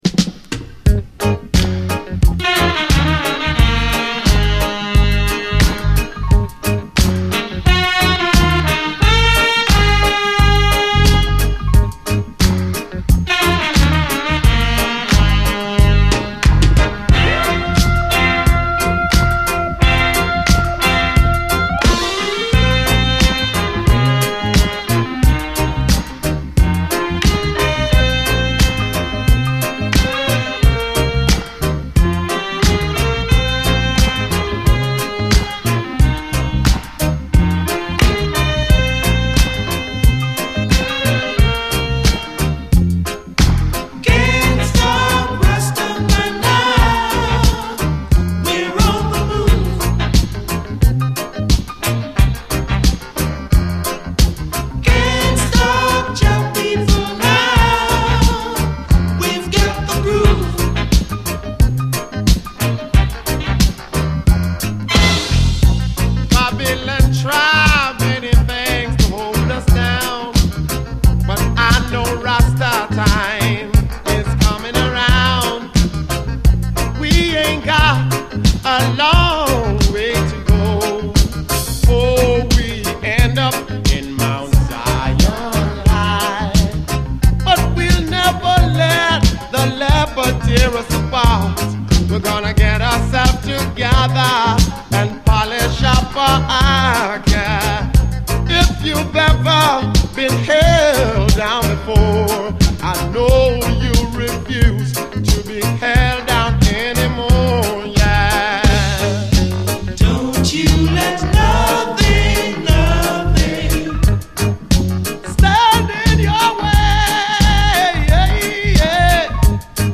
DISCO, REGGAE